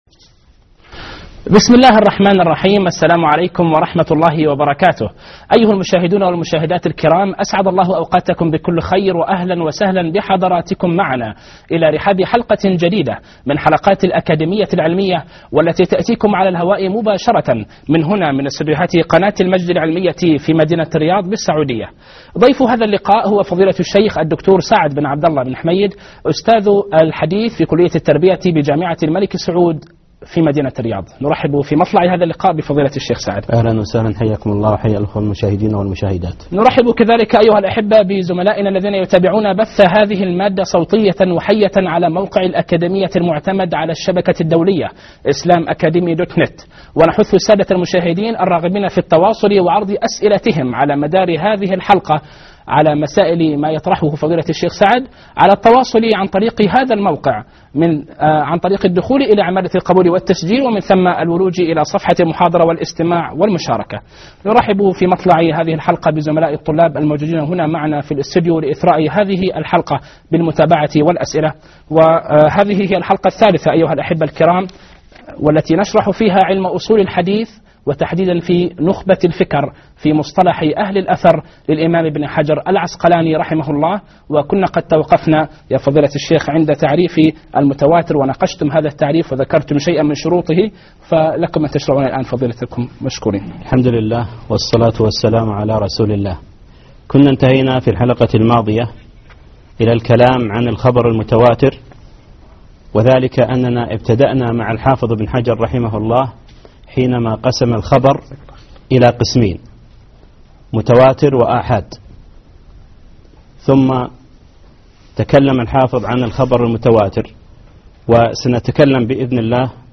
الدرس الثالث _ أخبار الآحاد